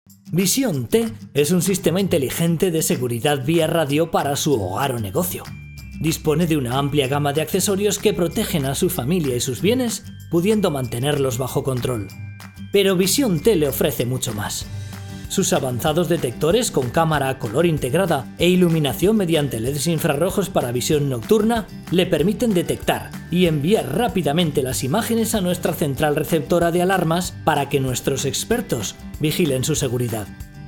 Tonos medios, jóvenes y brillantes. Tono medio de hombre.
Sprechprobe: Industrie (Muttersprache):
Middle tone of man..